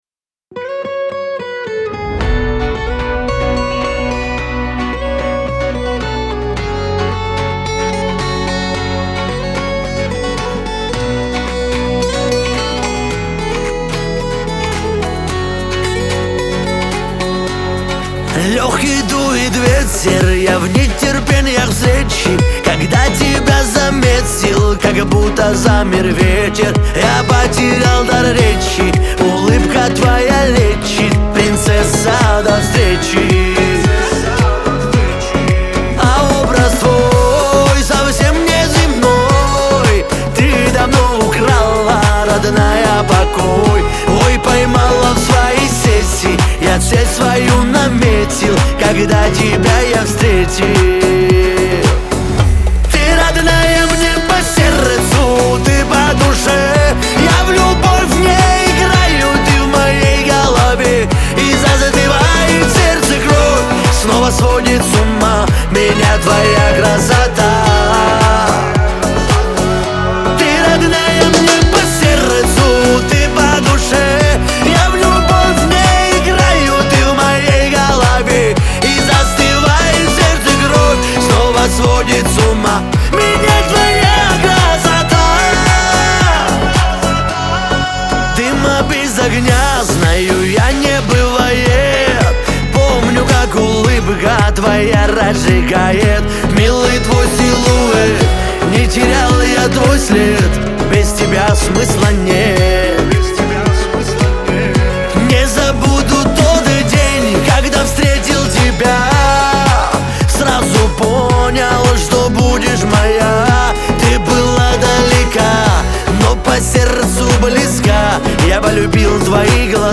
Кавказ поп